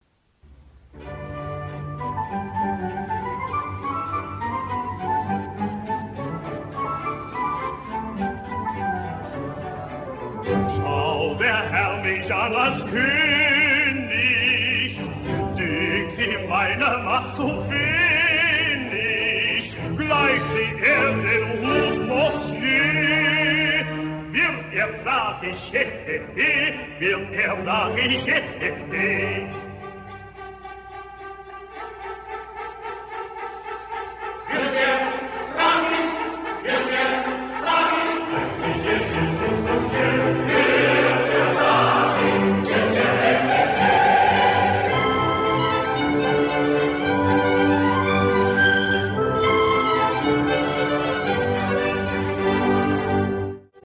musikalisch arrangiertes Lachen